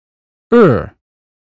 uk_phonetics_sound_dictionary_2023jun.mp3